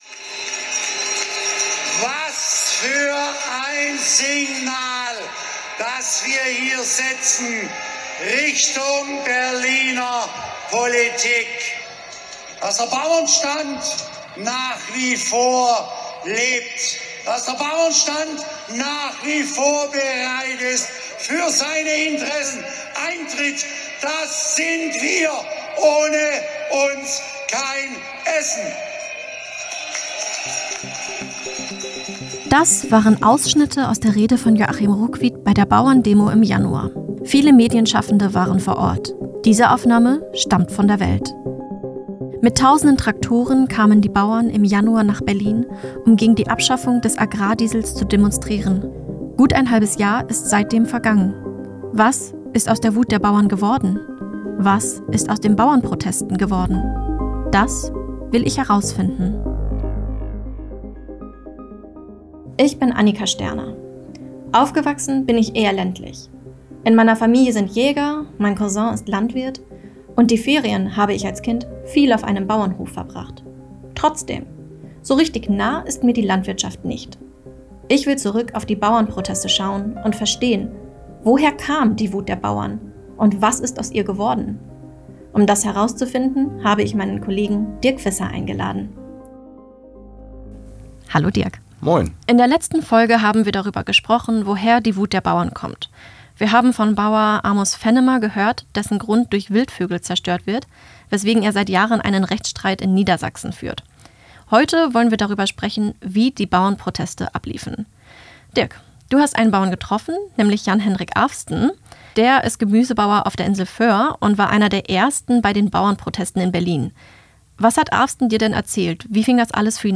Wir sprechen mit dem Landwirt